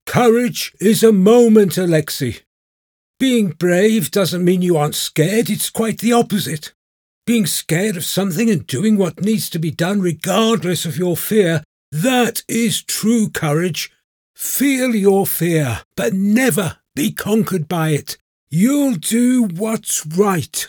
Adult (30-50) | Older Sound (50+)
1115Courage_Old_Wizard.mp3